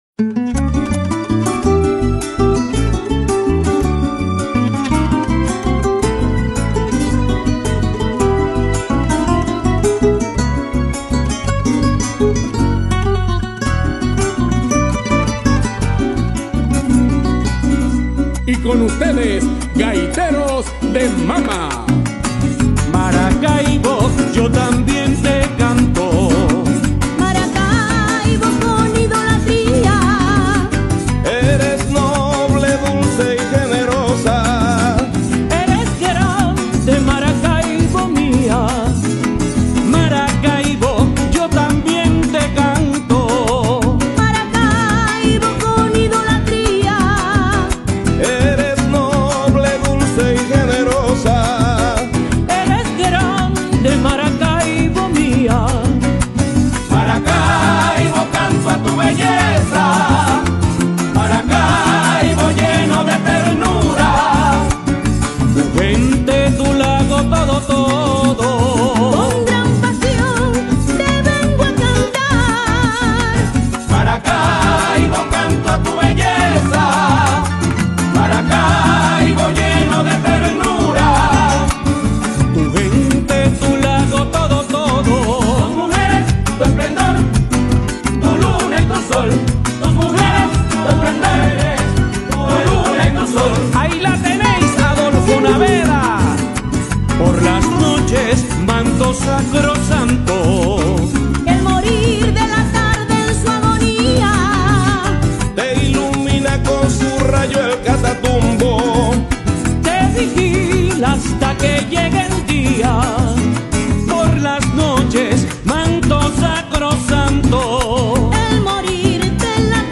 Gaitas 2011 en aacPlus